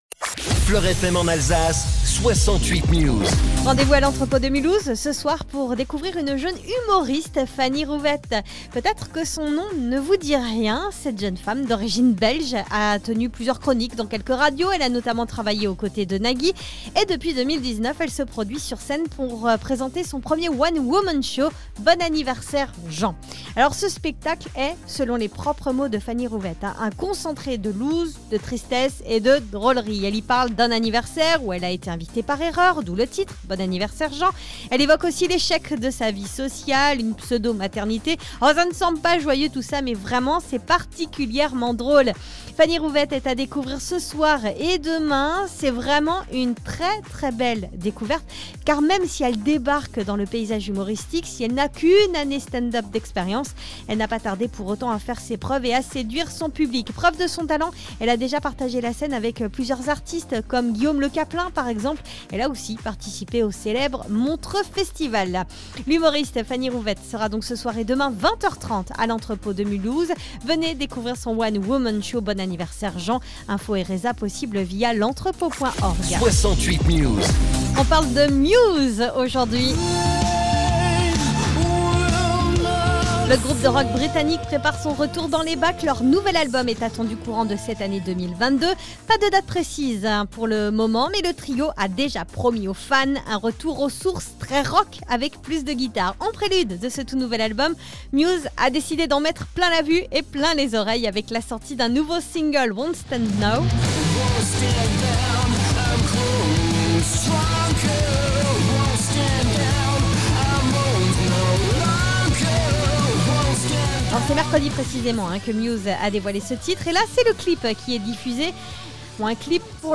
FLOR FM : Réécoutez les flash infos et les différentes chroniques de votre radio⬦